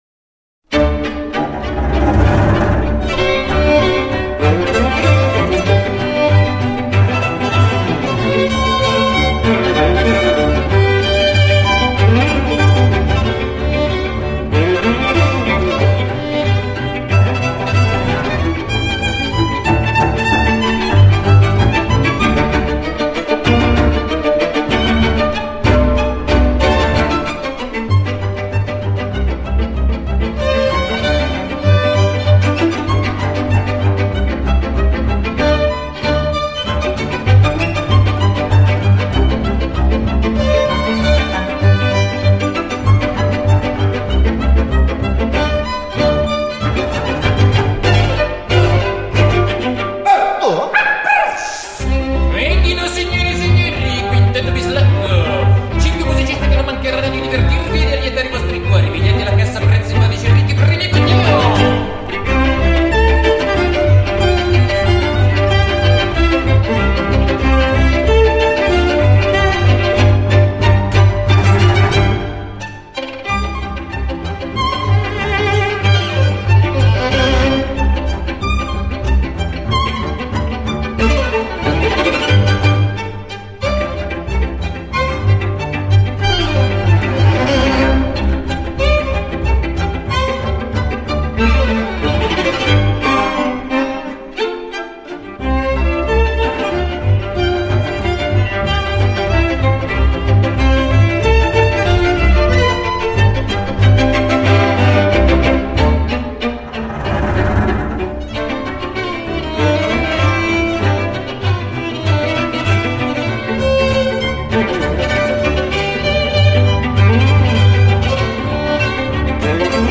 专辑格式：DTS-CD-5.1声道
五重奏
为低音质MP3